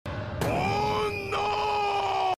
jojos-bizarre-adventure-joseph-joestar-oh-no.mp3